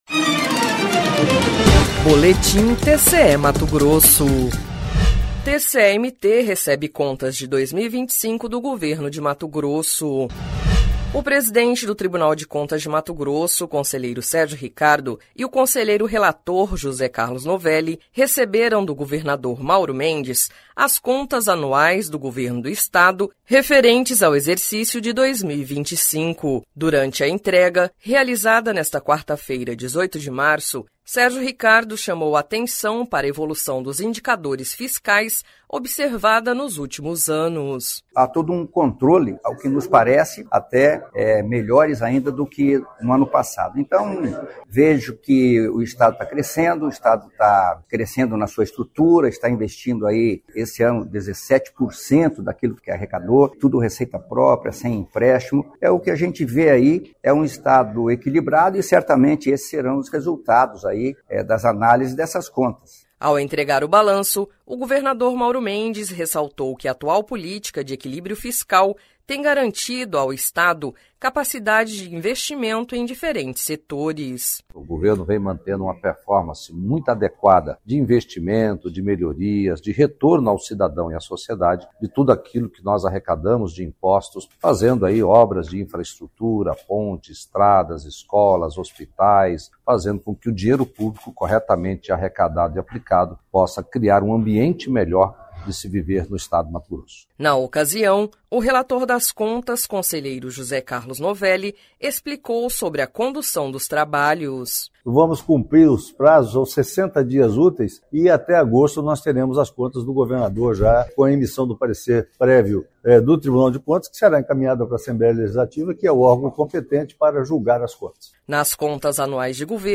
Sonora: Sérgio Ricardo – conselheiro-presidente do TCE-MT
Sonora: Mauro Mendes – governador de MT
Sonora: José Carlos Novelli – conselheiro do TCE-MT